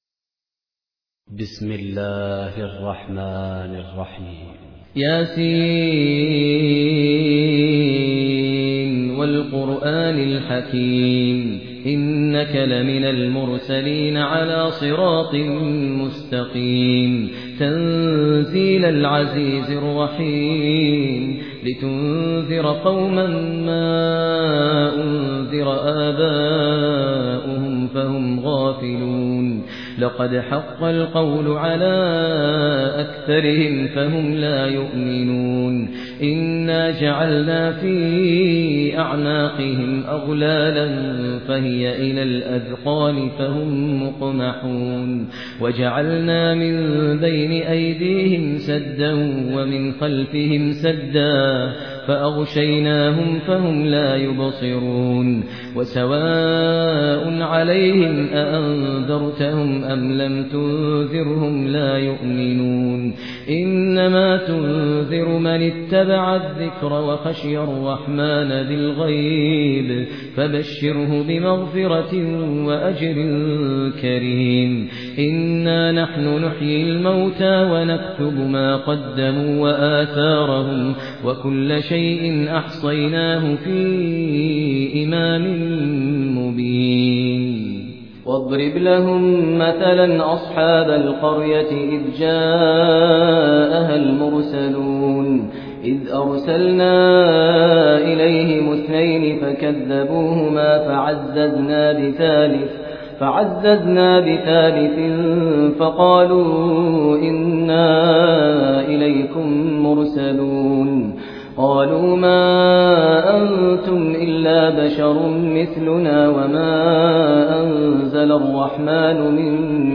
Mahir Muagli (Mahir el-Muaykli) sesinden Yasin-i Şerif dinle: Tarayıcınız ses dosyasını desteklemiyor.